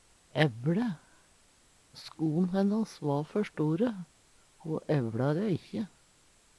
ævLe - Numedalsmål (en-US)